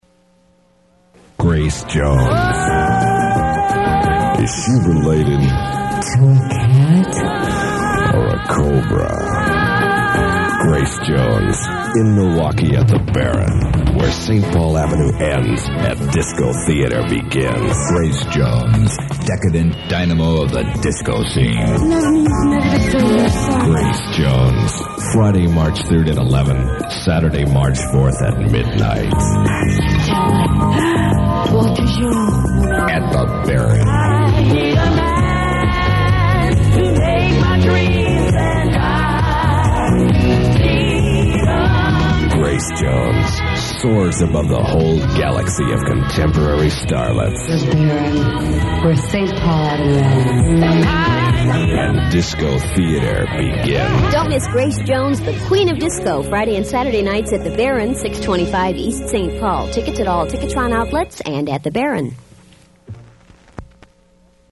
grace_jones_at_the_baron.mp3